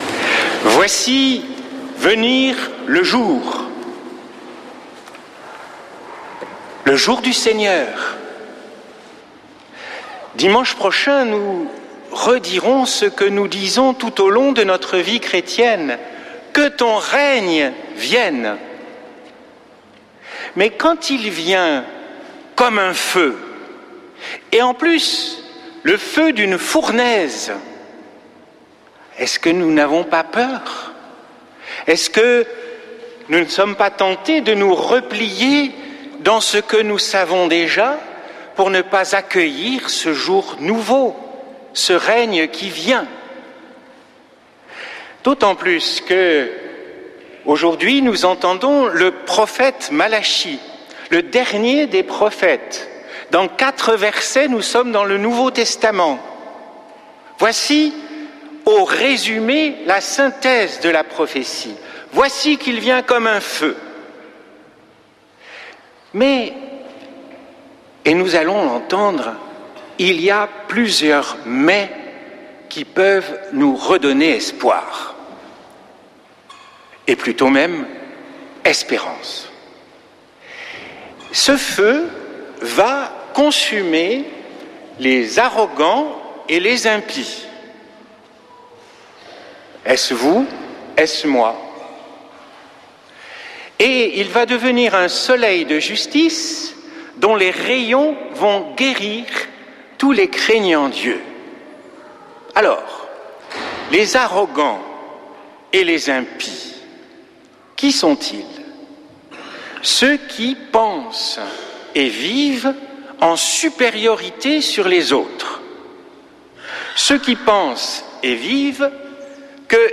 Messe depuis le couvent des Dominicains de Toulouse
homelie